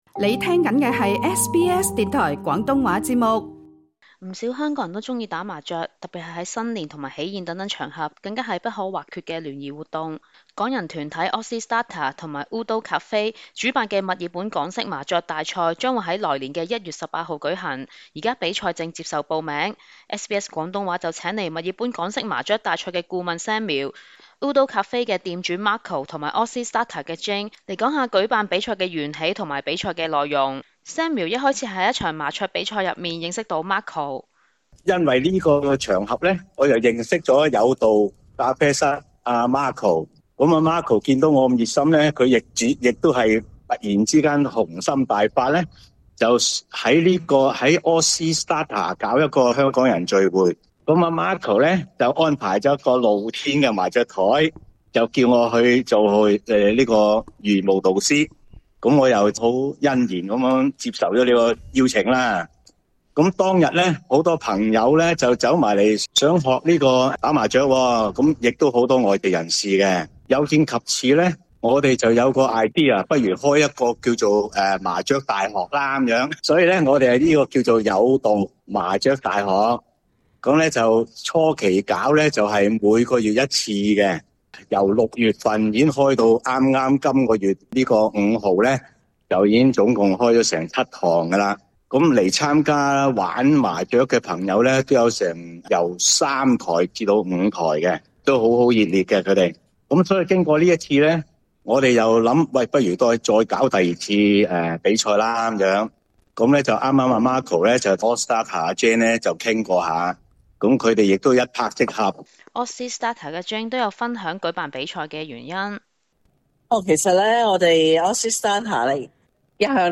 SBS廣東話節目 View Podcast Series Follow and Subscribe Apple Podcasts YouTube Spotify Download (14.81MB) Download the SBS Audio app Available on iOS and Android 不少港人都喜歡打麻雀，特別是在新年及喜宴等場合，更是不可或缺的聯誼活動。